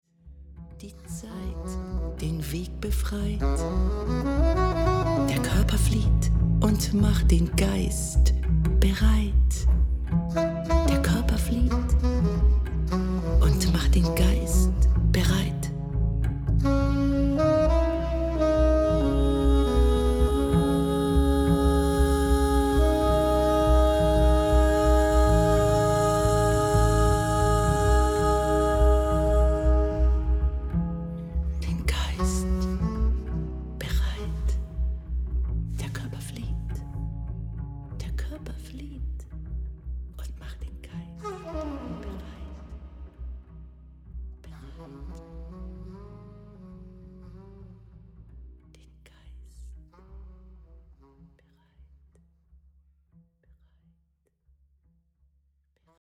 Neue Mikrofon für Saxophon/Aufnahmetechnik
So gut wie keine EQing. Etwas Kompression und natürlich etwas Raum...